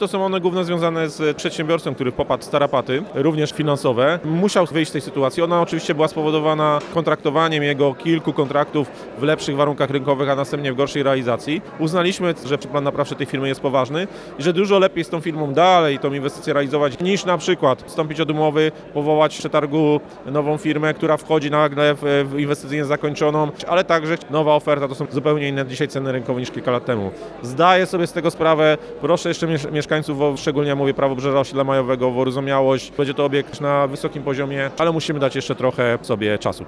Realizacja projektu przesunęła się aż o trzy lata względem pierwotnych założeń. Do sprawy odniósł się zastępca prezydenta Szczecina, Michał Przepiera.